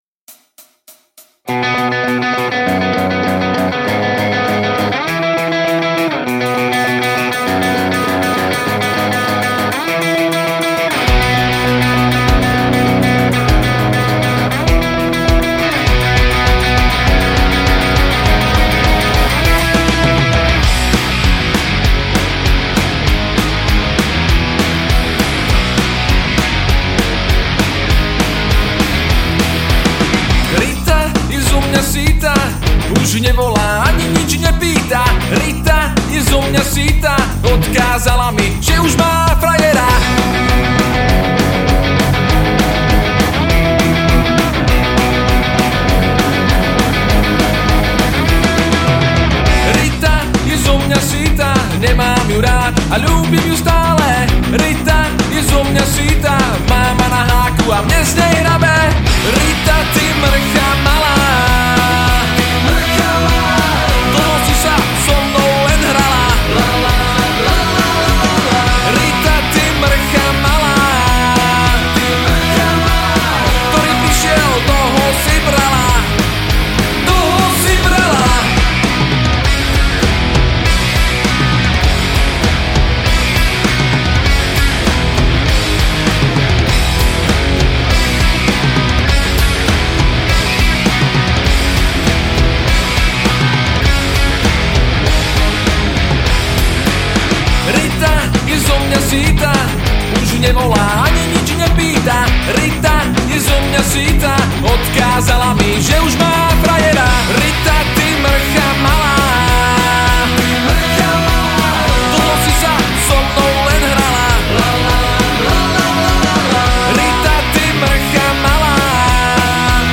Žánr: Rock
gitara, spev
basgitara, spev
bicie, spev